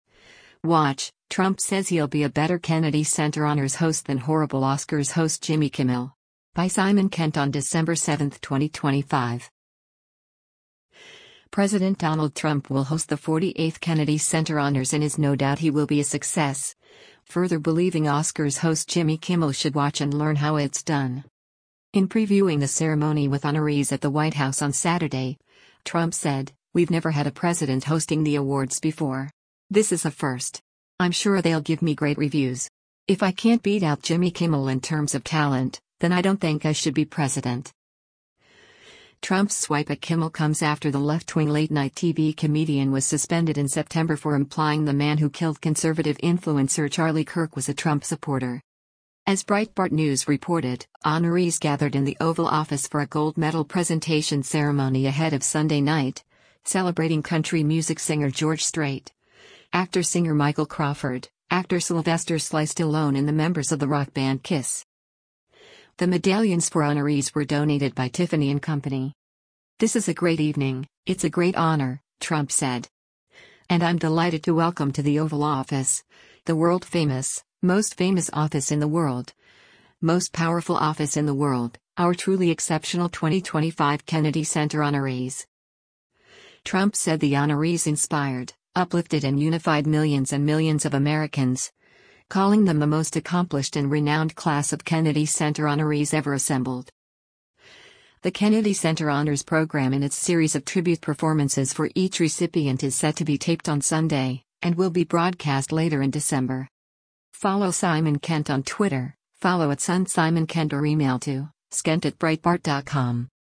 In previewing the ceremony with honorees at the White House on Saturday, Trump said,  “We’ve never had a President hosting the awards before. This is a first. I’m sure they’ll give me great reviews… If I can’t beat out Jimmy Kimmel in terms of talent, then I don’t think I should be President.”
As Breitbart News reported, honorees gathered in the Oval Office for a gold medal-presentation ceremony ahead of Sunday night, celebrating country music singer George Strait, actor-singer Michael Crawford, actor Sylvester “Sly” Stallone and the members of the rock band Kiss.